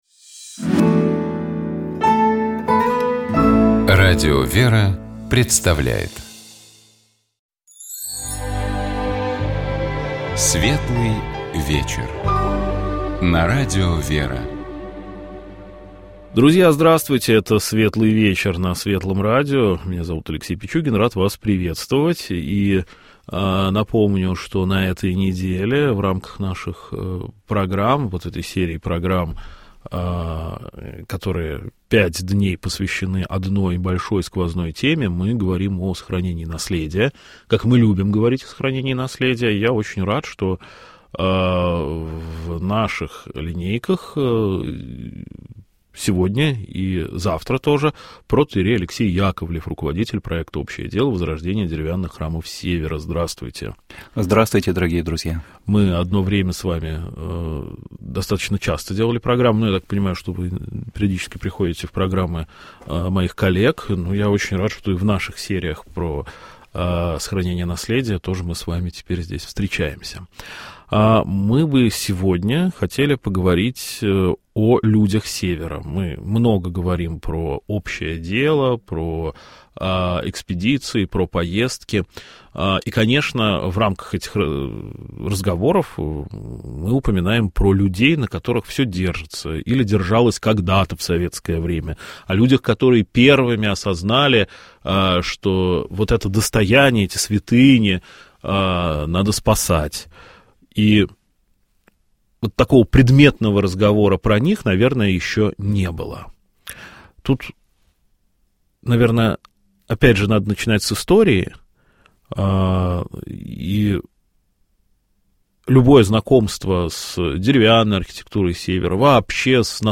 Программа «Светлый вечер» — это душевная беседа ведущих и гостей в студии Радио ВЕРА. Разговор идет не о событиях, а о людях и смыслах.